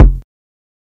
Kick [Triggerz].wav